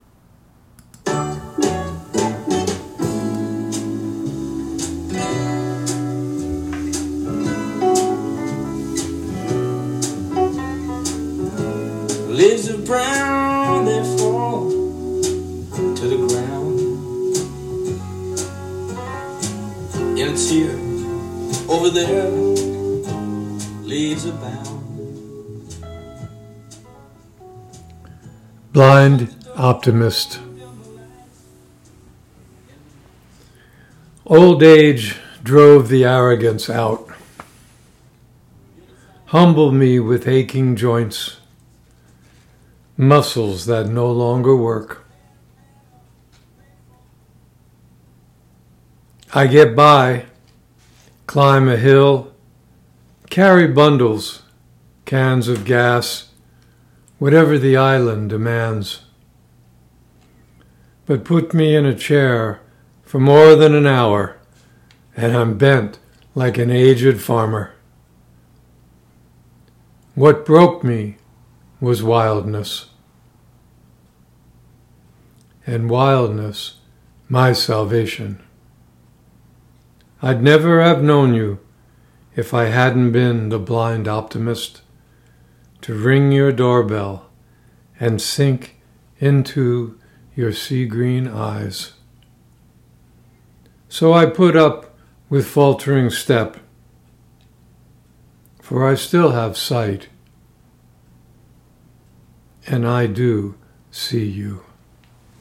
Reading of “Blind Optimist” with music by Van Morrison